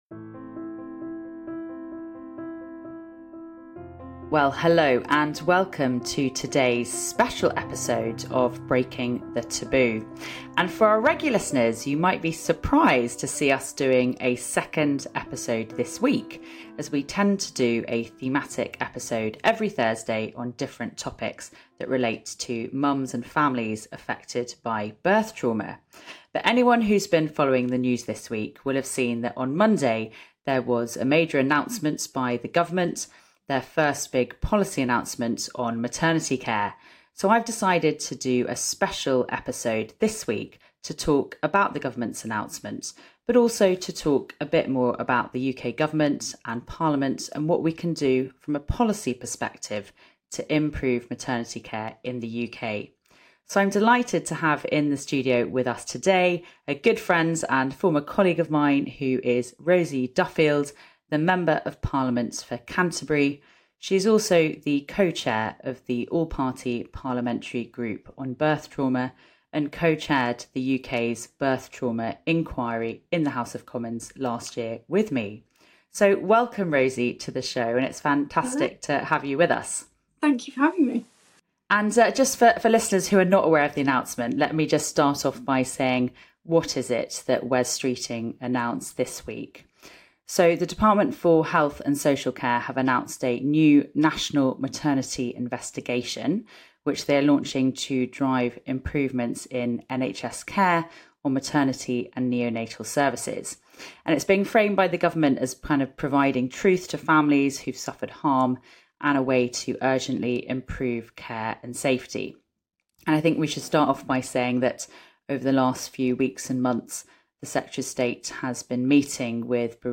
This week in a special episode our host Theo Clarke is joined by Rosie Duffield MP, the Chair of the All Party Parliamentary Group for Birth Trauma in the UK Parliament. We discuss the Health Secretary’s recent announcement of a rapid national maternity investigation.